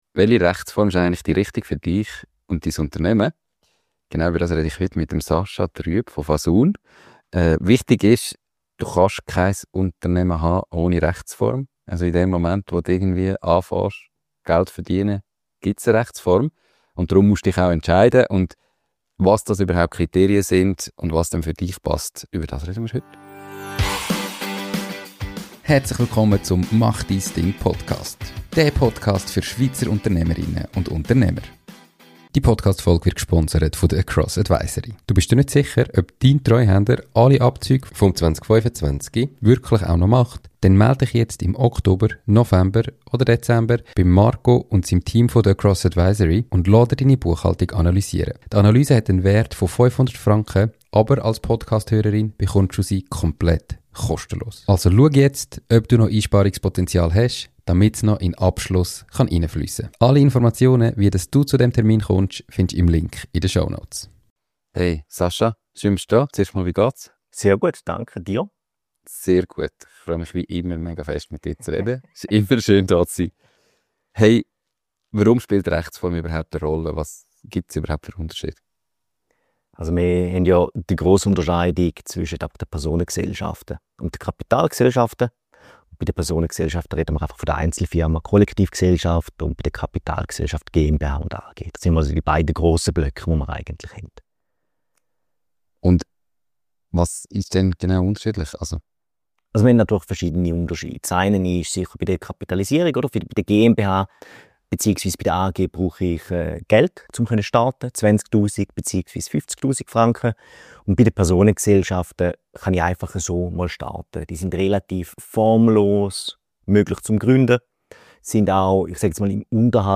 Der Podcast für Schweizer Unternehmer, Selbstständige und alle, die es noch werden wollen! Erfolgreiche Unternehmer und Selbstständige aus der Schweiz erzählen dir ihre Geschichte.